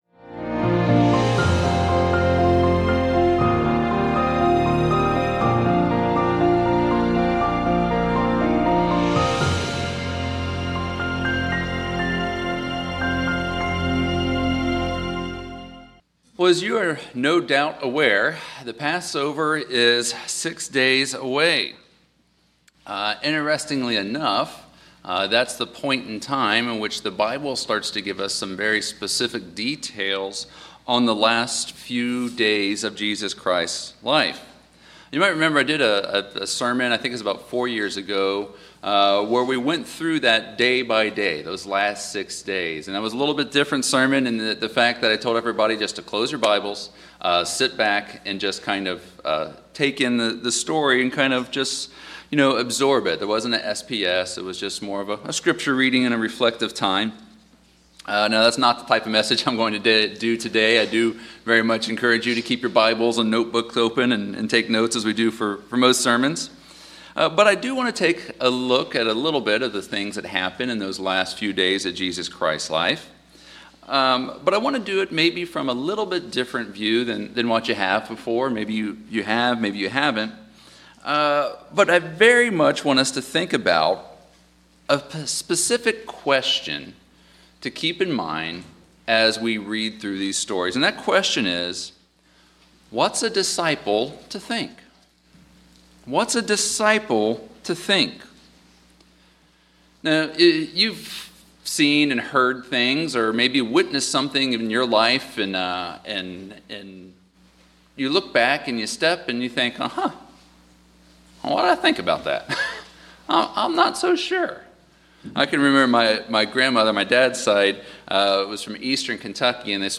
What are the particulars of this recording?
Given in Charlotte, NC Hickory, NC Columbia, SC